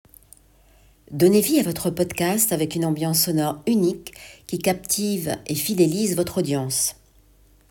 Bandes-son
49 - 60 ans - Mezzo-soprano